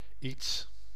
Ääntäminen
IPA: [iːts]